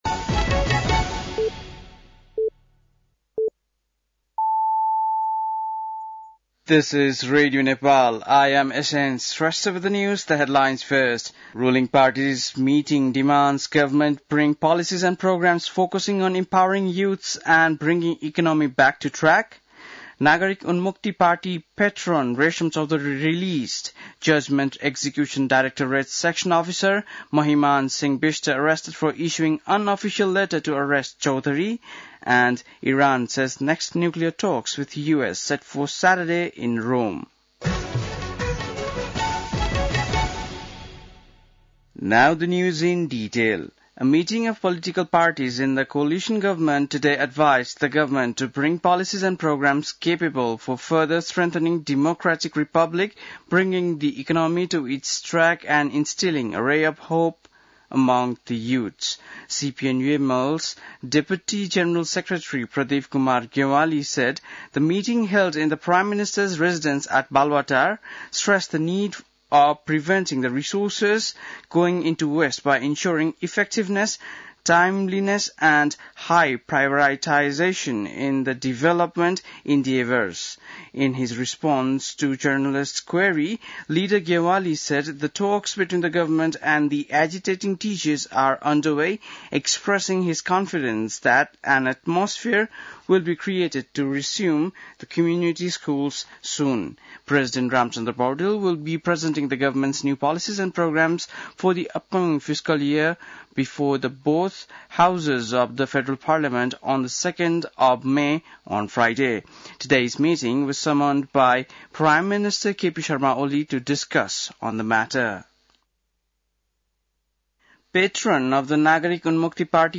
बेलुकी ८ बजेको अङ्ग्रेजी समाचार : १७ वैशाख , २०८२
8-pm-english-news-1-17.mp3